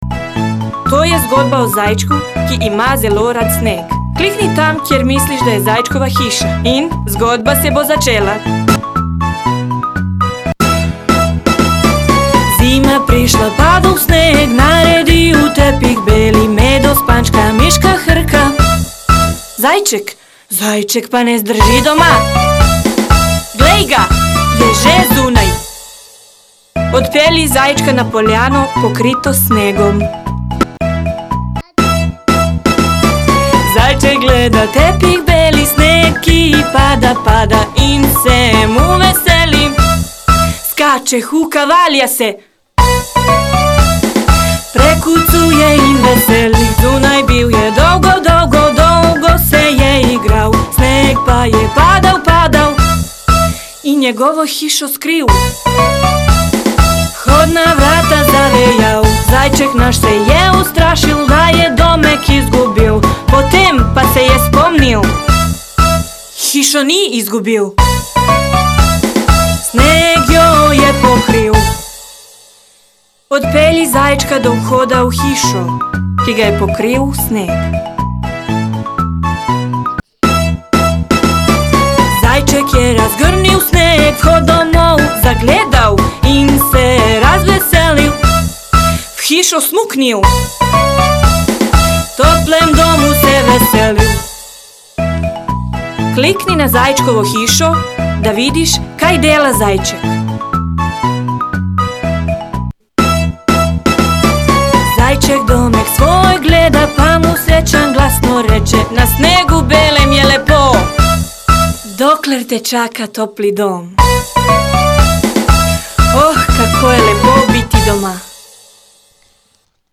Zvok sem zajel direktno iz igre.
Miška hrka? Zajček se huka?!?